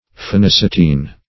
Phenacetin \Phe*nac"e*tin\, Phenacetine \Phe*nac"e*tine\, n.
phenacetine.mp3